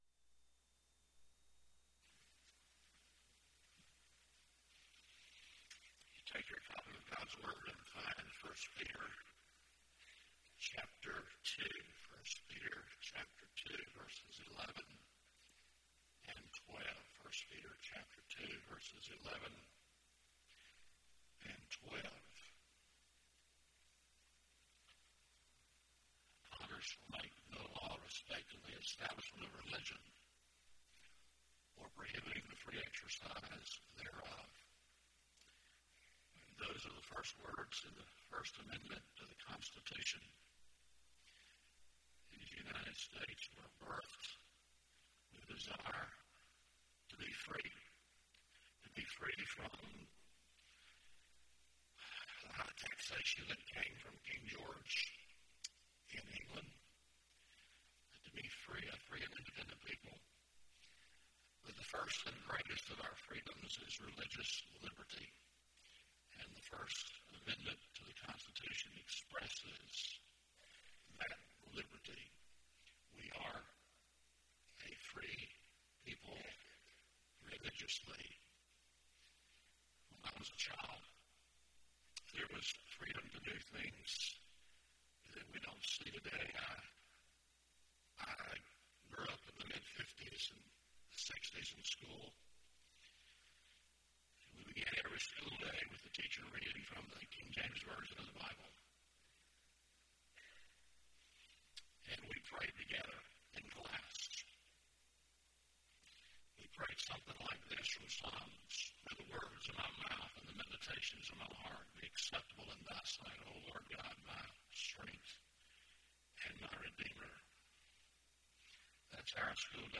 Lakeview Baptist Church - Auburn, Alabama
Sermon